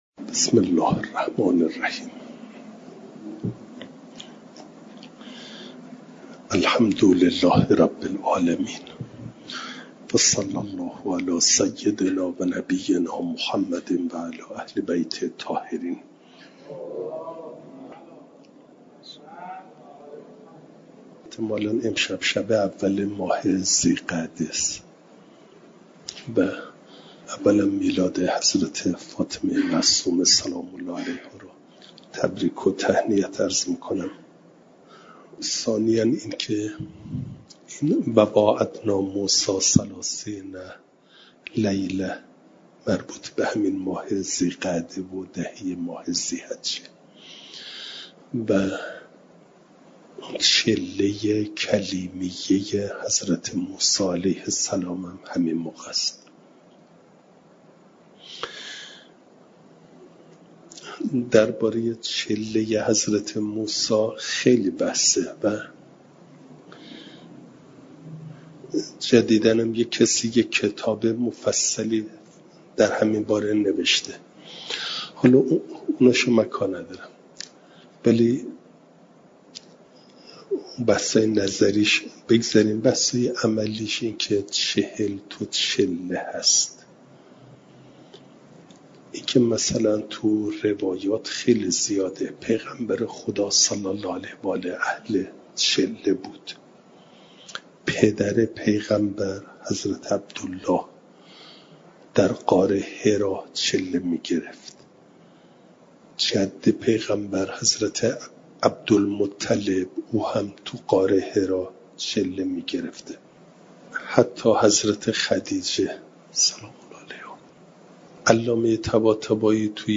درس تفسیر مجمع البیان